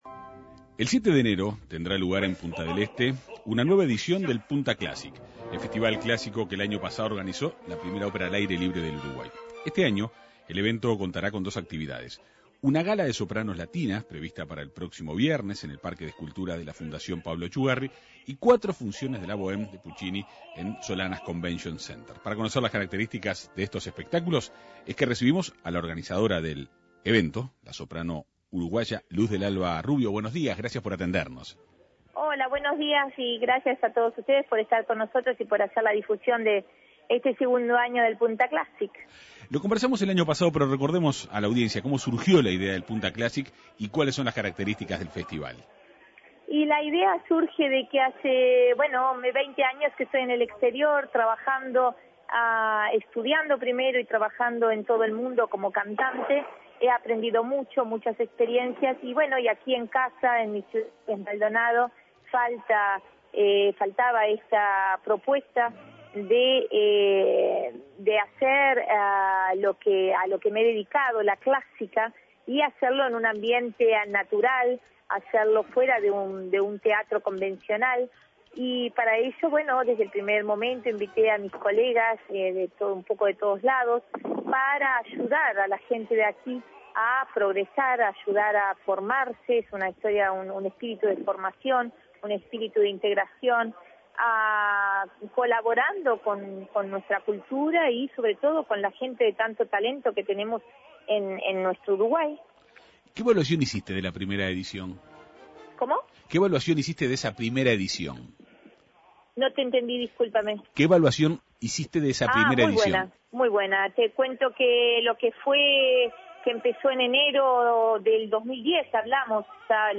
conversó con la Segunda Mañana de En Perspectiva.